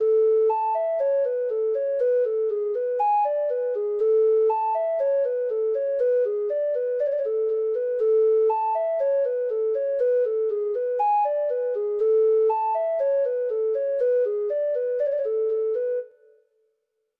Traditional Music of unknown author.
Reels
Irish